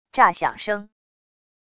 炸响声wav音频